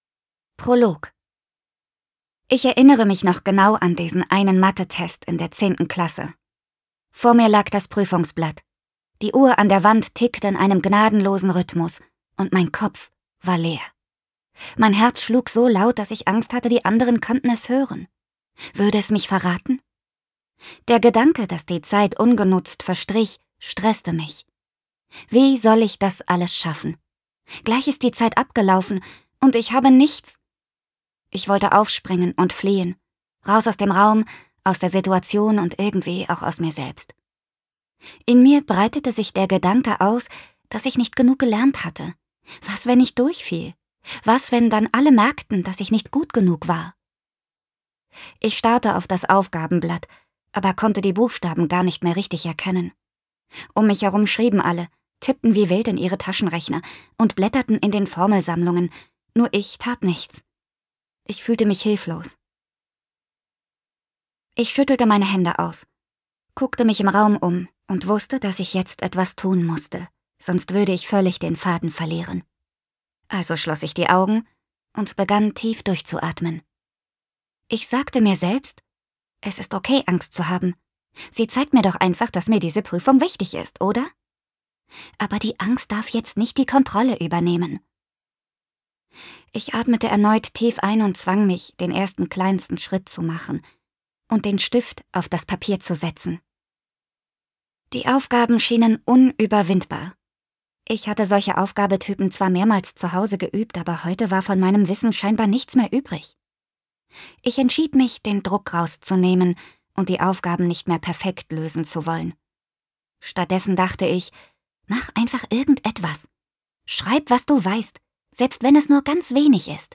Leseprobe
Sprecherin